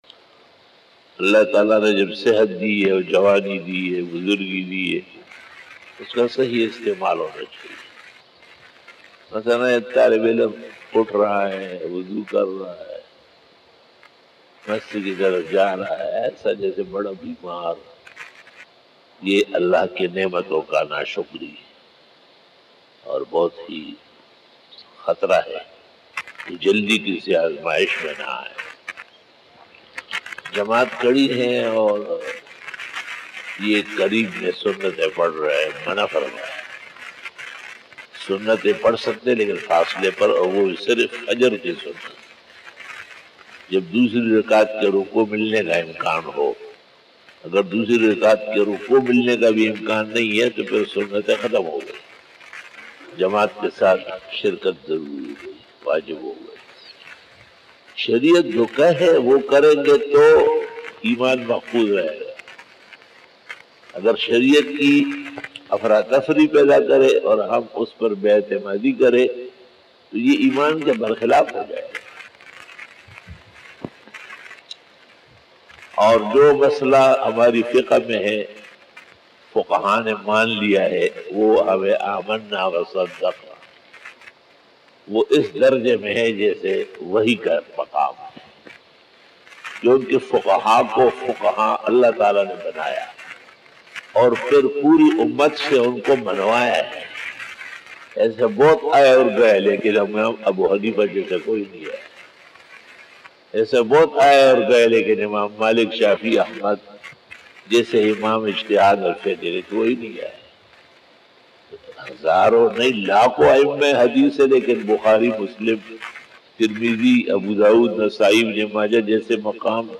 Fajar bayan 07 October 2020 (19 Safar ul Muzaffar 1442HJ) Wednesday
بعد نماز فجر بیان 07 اکتوبر 2020ء بمطابق 19 صفر 1442ھ بروزبدھ